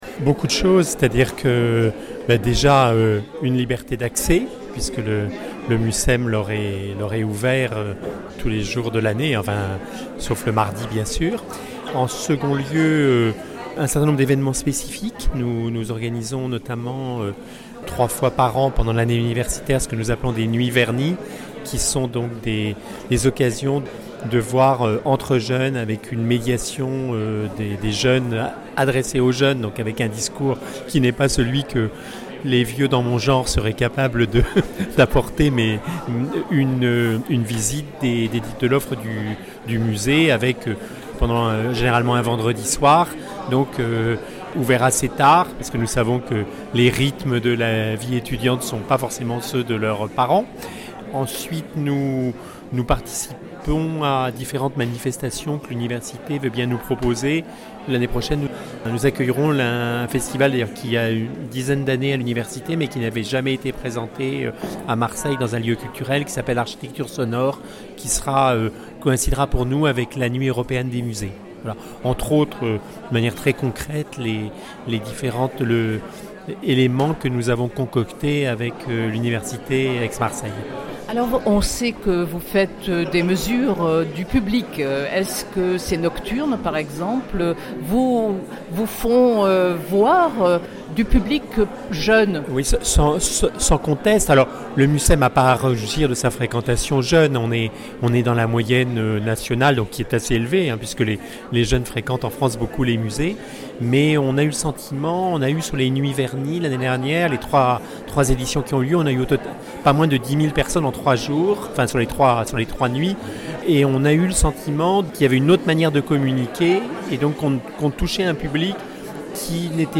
Des Comités de pilotage composés de techniciens et d’élus assurent le suivi de ces conventions afin qu’elles ne soient pas «des chiffons de papier». son_copie_petit-57.jpg Jean-François Chougnet, Président du Mucem revient sur les manifestations, actions, réalisées en commun “une trentaine emblématique”